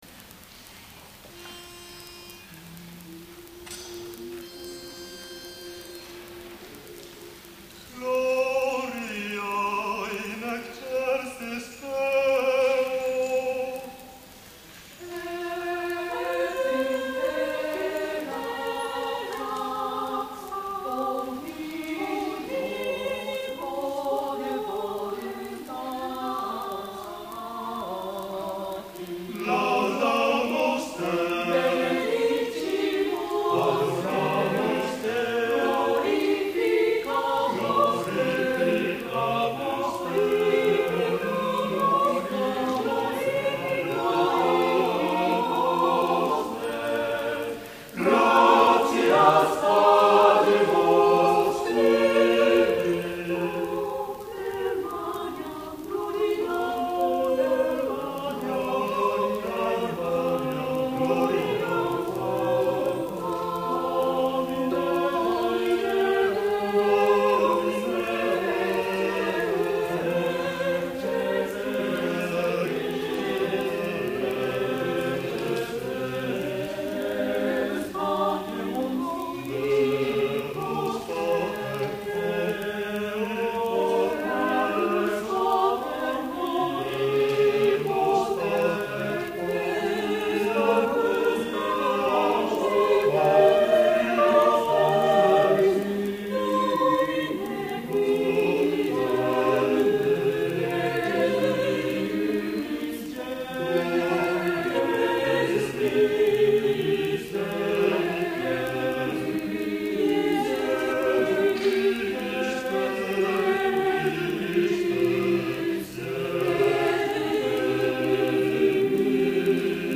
第35回野田市合唱祭
野田市文化会館
Mass for four voices より　William Byrd　バード